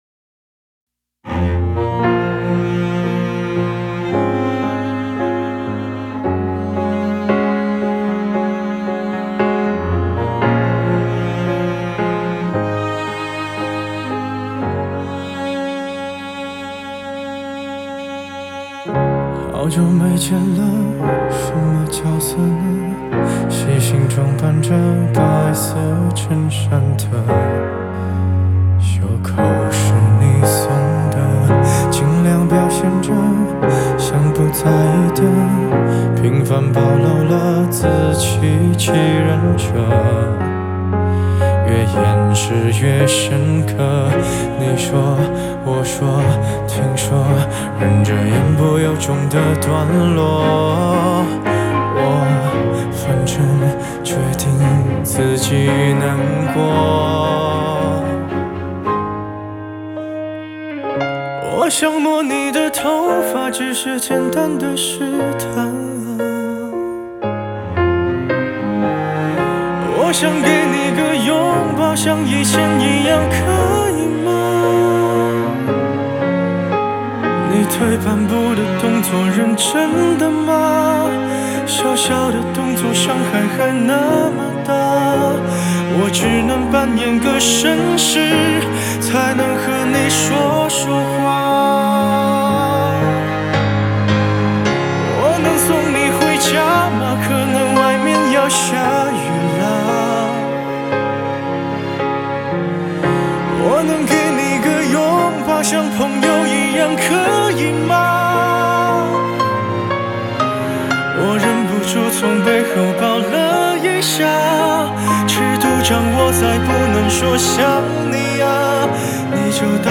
Ps：在线试听为压缩音质节选，体验无损音质请下载完整版